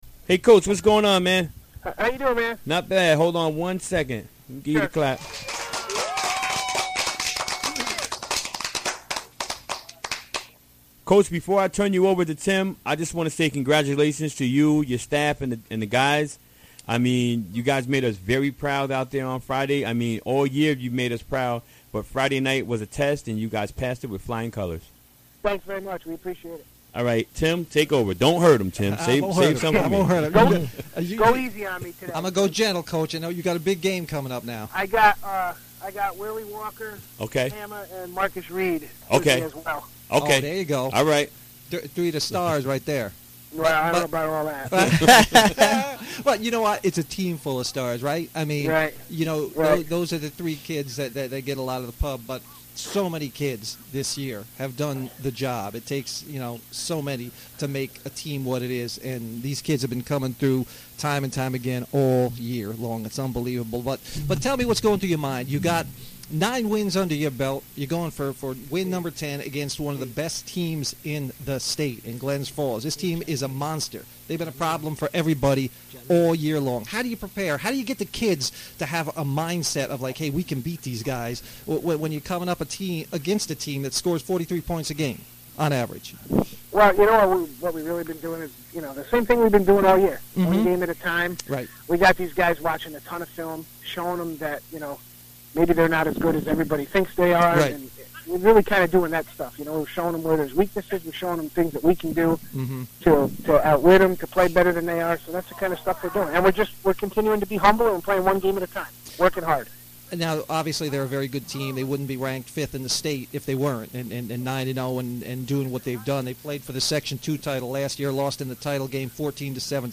Recorded during the WGXC Afternoon Show Wednesday, November 2, 2016.